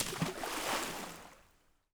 SPLASH_Small_05_mono.wav